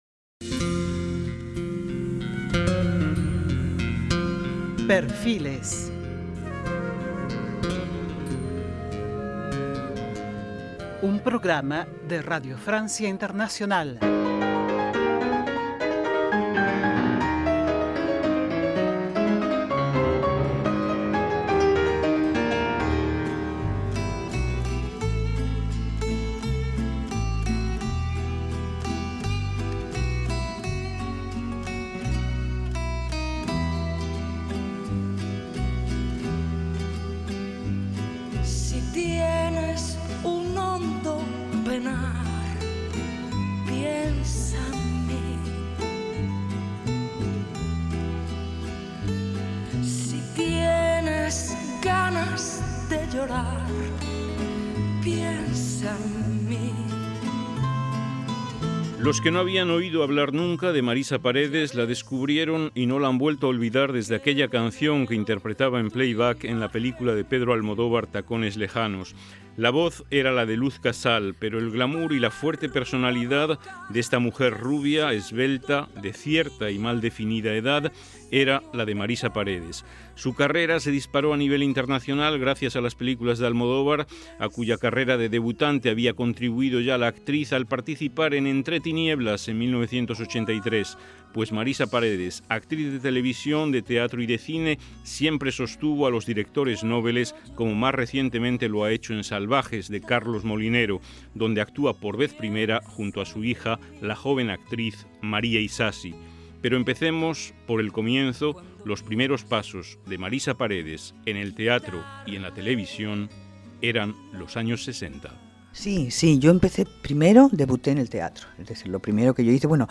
Mirando en mis archivos personales he encontrado esta entrevista de veinte minutos que hice con ella en 2002 en RFI y que comparto aquí con nuestros lectores.